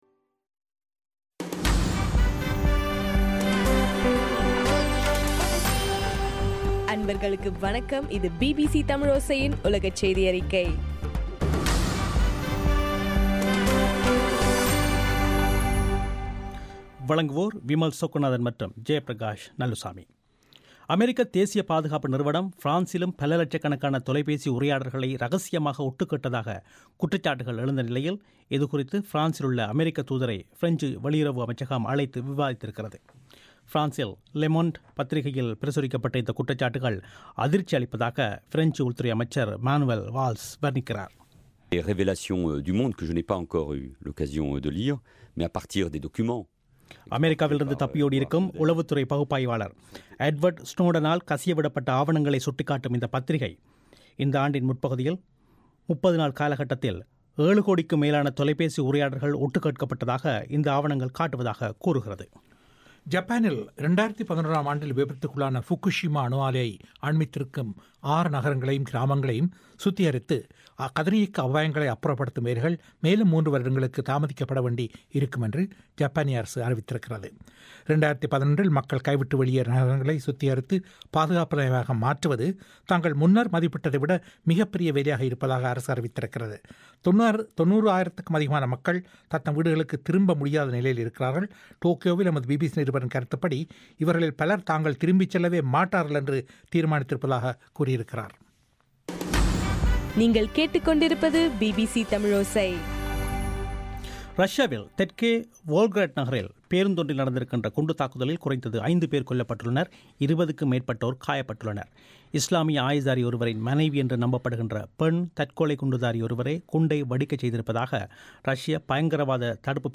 அக்டோபர் 21 2013 பிபிசி தமிழோசை உலகச் செய்திகள்